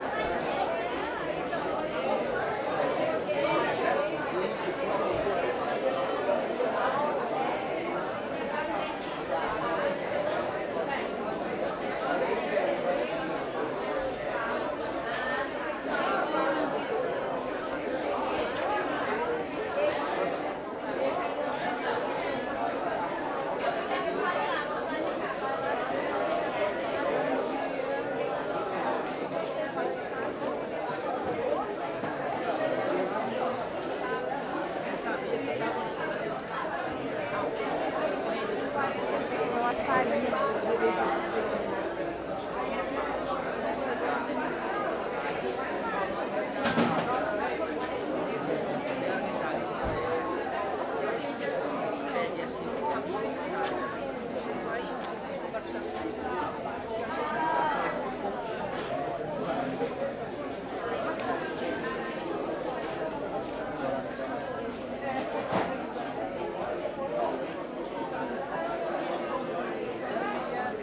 Feira alto paraiso de manha muitas pessoas perto alguns jovens
Alto Paraíso de Goiás Surround 5.1
CSC-11-039-LE - Feira alto paraiso de manha muitas pessoas perto alguns jovens.wav